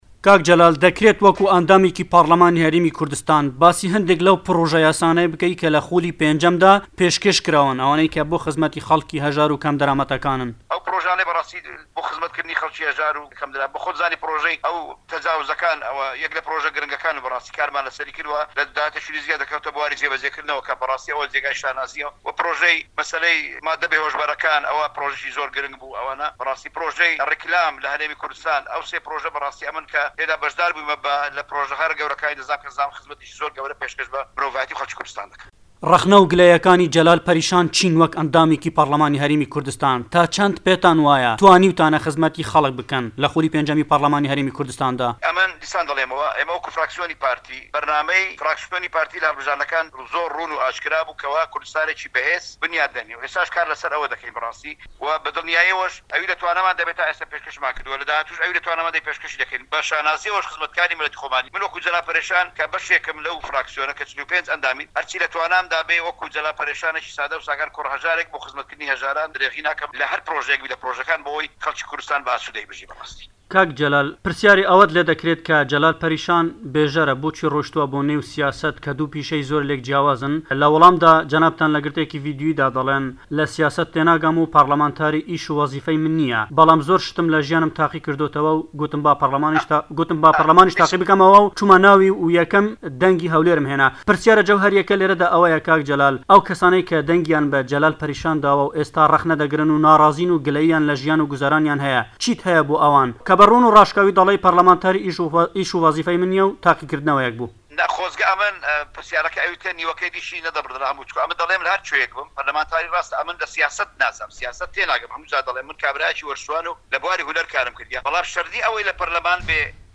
جەلال پەرێشان لە میانەی وتووێژێکیدا لەگەڵ بەشی کوردی دەنگی ئەمەریکا گوتی" لە سیاسەت تێناگەم و پەرلەمانتاری وەکو وەزیفە ئیشی من نییە کابرایەکی وەرزشوانم و لە بواری هونەری کارم کردووە مەرج نییە ئەوەی لە پەرلەمان بێت دەبێت سیاسی بێت لە وڵاتان ئەوانەی دەچنە پەرلەمان هەموویان پسپۆرن لە بوارێک ئەم وڵاتە بەس سیاسییەکان بەڕێوەی نابەن، دوو کۆڵەکەی سەرەکی ژیانی هەموو کۆمەڵگایەک گوزارشت لە هونەر و وەرزش دەکات.